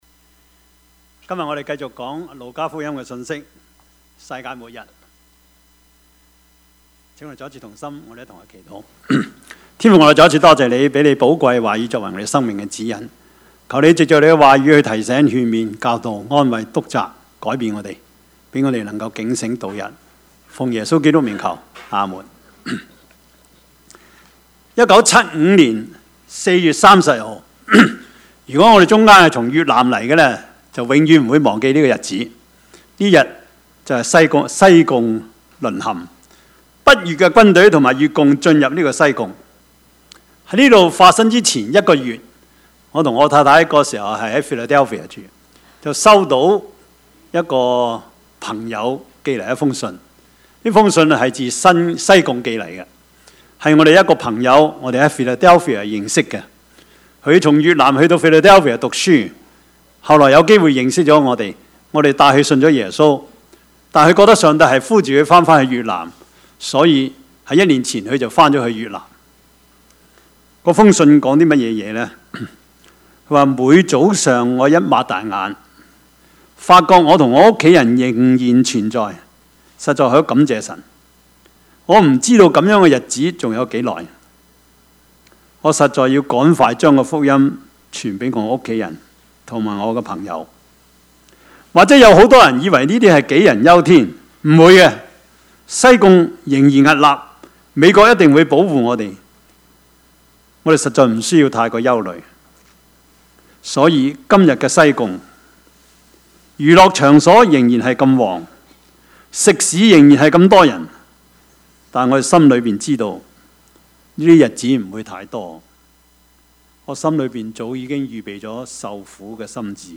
Passage: 路加福音 21:25-38 Service Type: 主日崇拜
Topics: 主日證道 « 但願這事普傳天下 預備他的道路 »